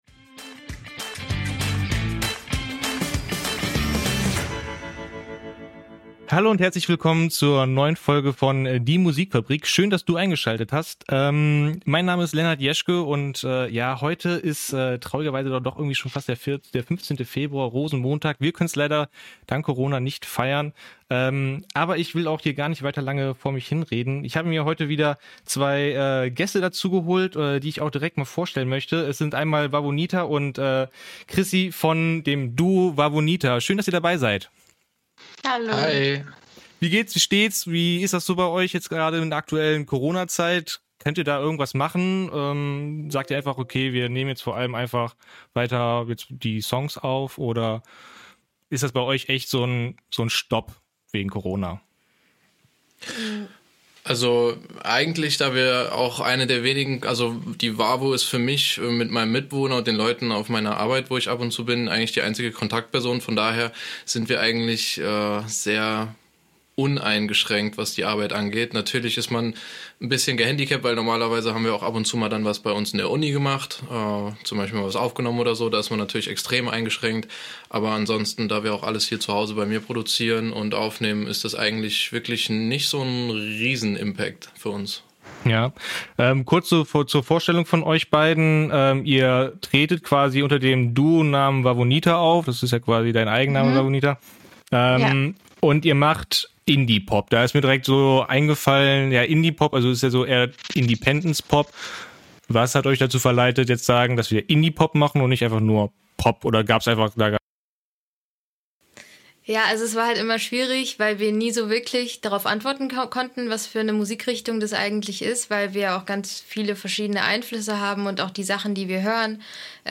Kölner Indie-Pop Duo